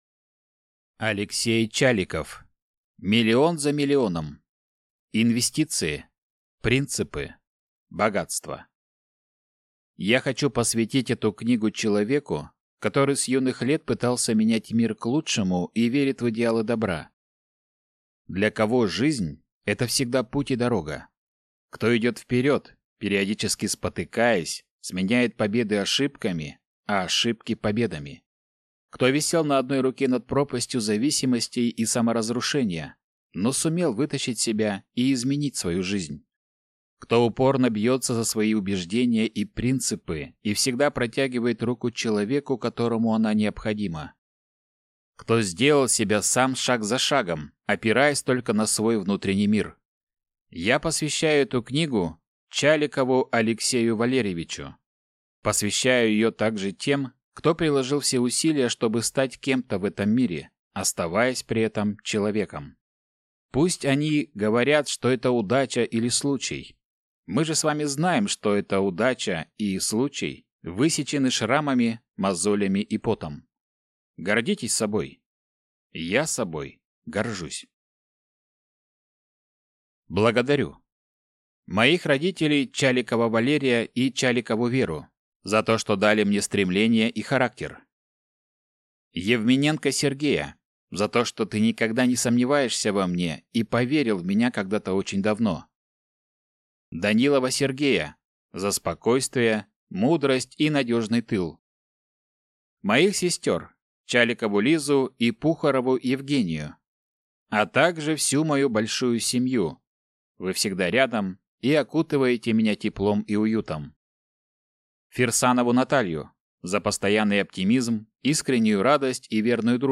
Аудиокнига Миллион за миллионом. Инвестиции. Принципы. Богатство | Библиотека аудиокниг